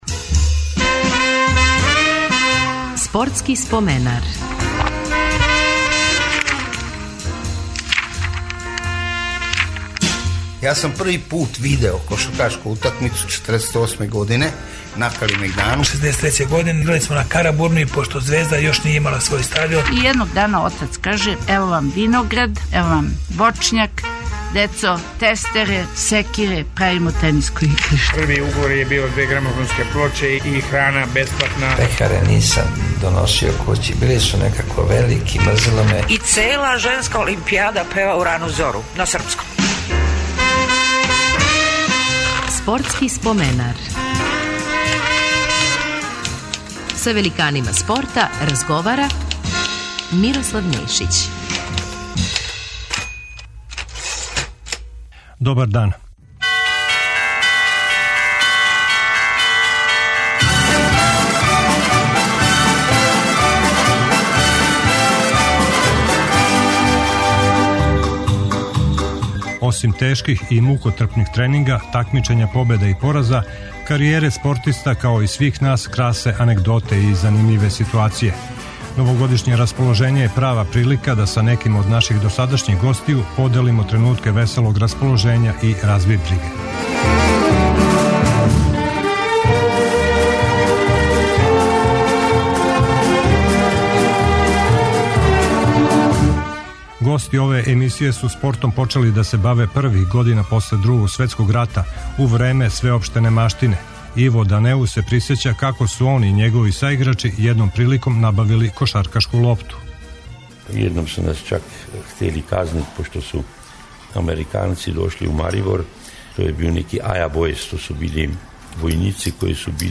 Пошто смо још у празничном расположењу наши познати асови ће причати анегдоте из својих каријера. Славни маратонац Фрањо Михалић ће се присетити како је био бржи од коњских запрега на Авалском путу, али и од воза.